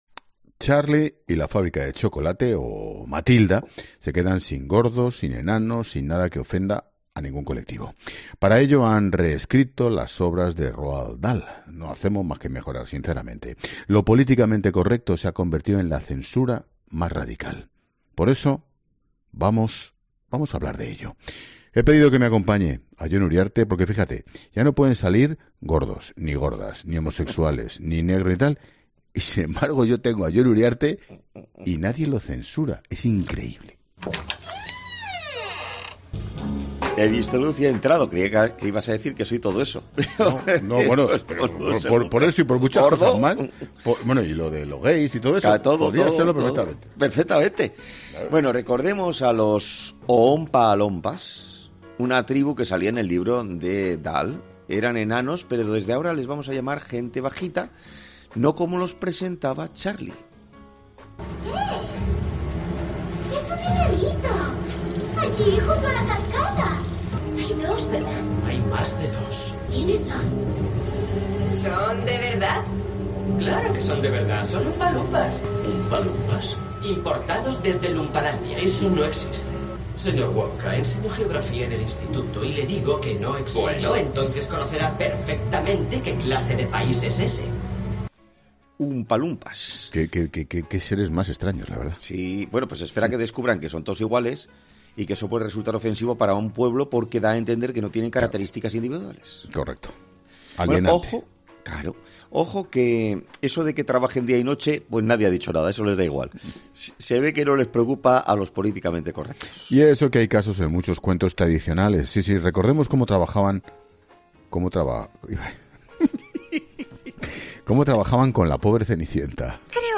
Por último, han querido bromear ambos con que “podrían revisar cosas que hoy en día serían inaceptables pero que todavía no han descubierto los censores”, a la vez que introducían un corte sobre la legendaria serie 'Mazinger Z' en la que el personaje de Afrodita sufre una operación indeseada de pecho.